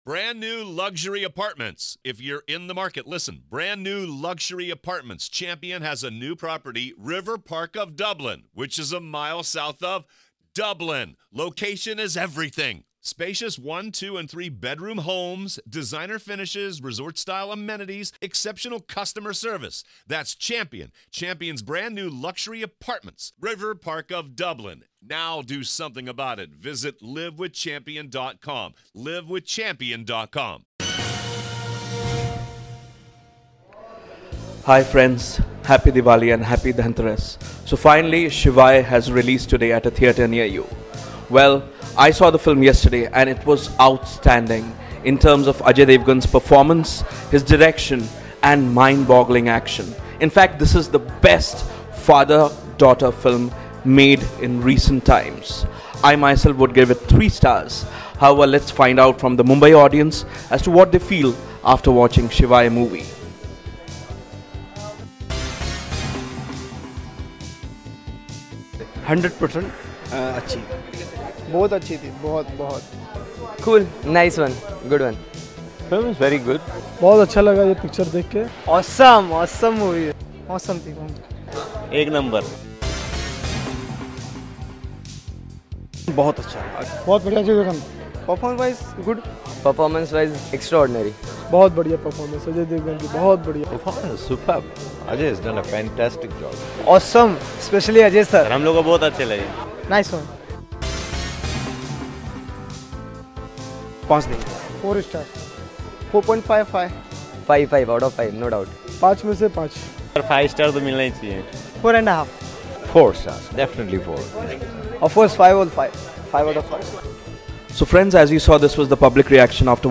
SHIVAAY PUBLIC REVIEW | First Day First Show